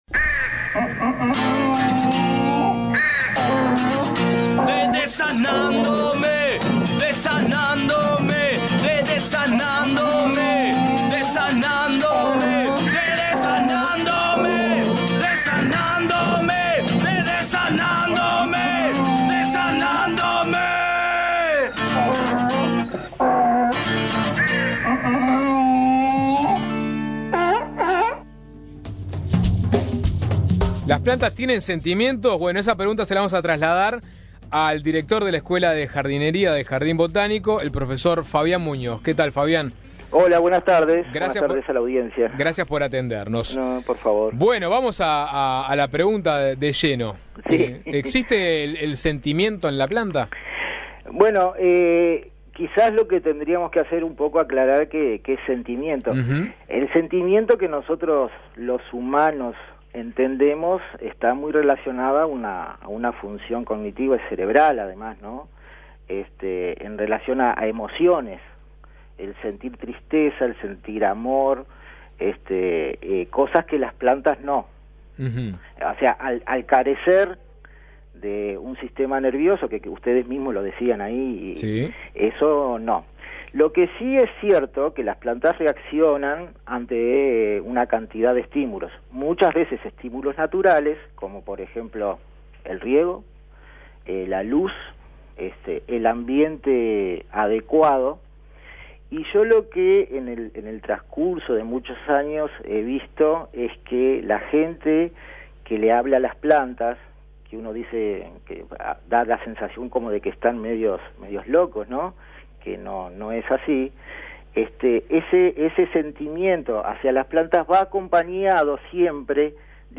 Una charla que fue de gansos a tomates, y no por los conductores, sino por los temas tratados, nos llevó a esta pregunta.